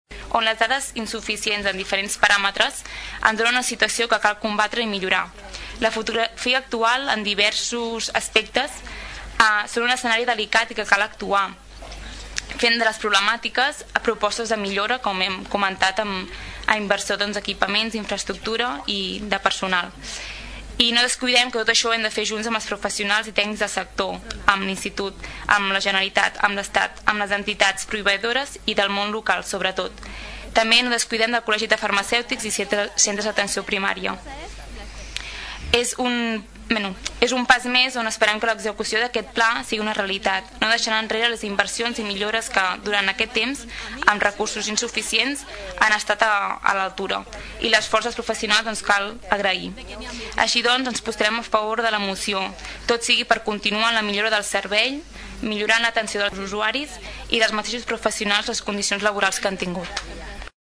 Finalment, en el grup de govern del PdeCat, la regidora Bàrbara Vergés anunciava el vot favorable del grup, justificant la greu situació del sector segons dades i paràmetres obtinguts recentment.